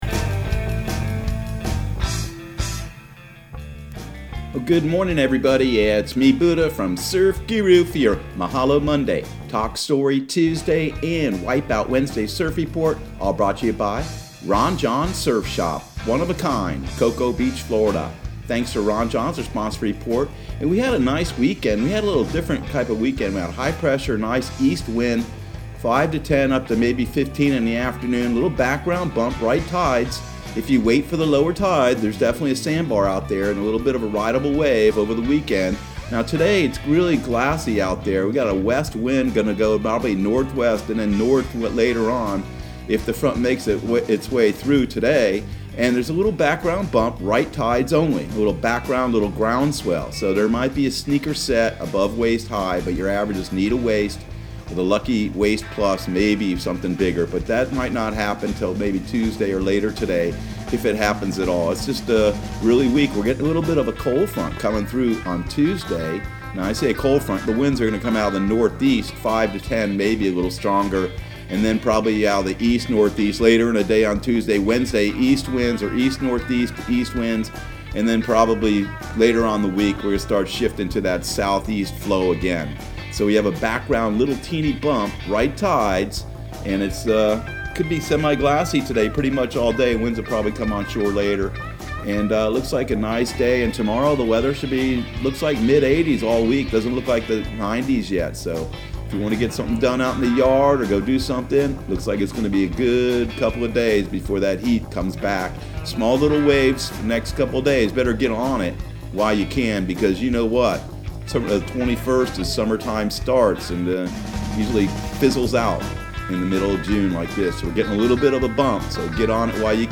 Surf Guru Surf Report and Forecast 06/15/2020 Audio surf report and surf forecast on June 15 for Central Florida and the Southeast.